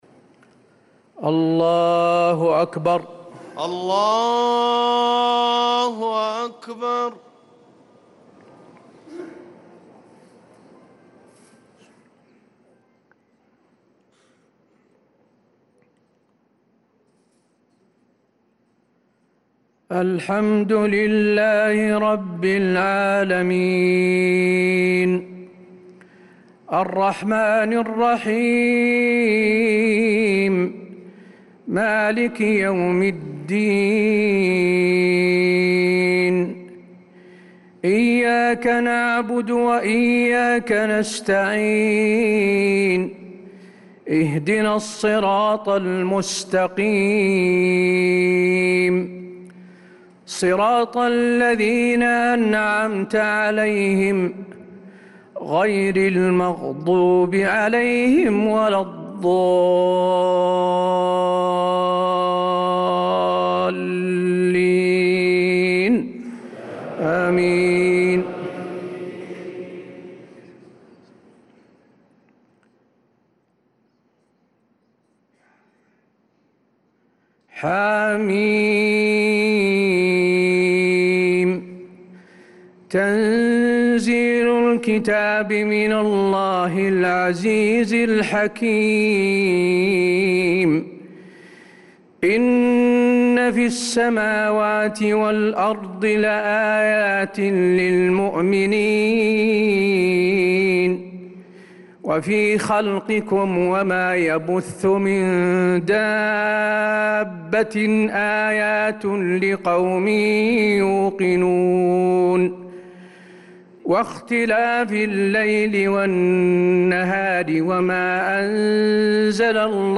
صلاة العشاء للقارئ حسين آل الشيخ 16 رجب 1446 هـ
تِلَاوَات الْحَرَمَيْن .